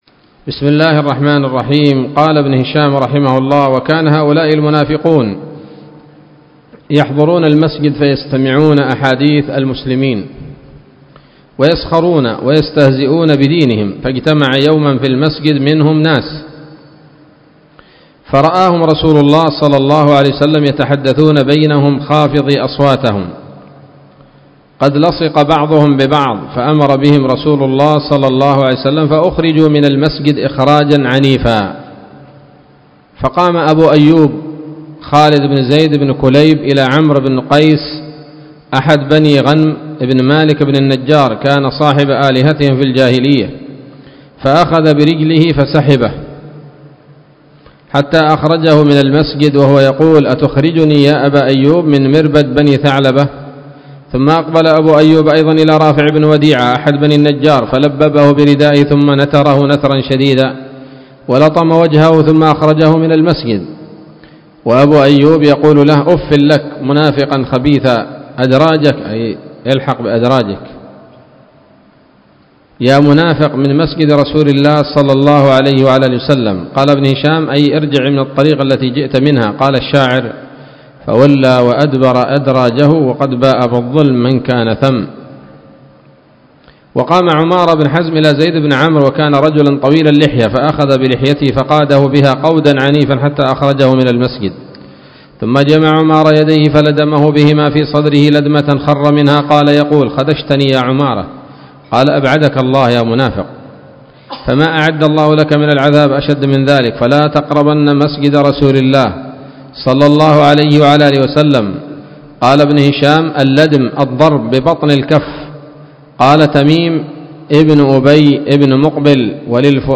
الدرس السابع والثمانون من التعليق على كتاب السيرة النبوية لابن هشام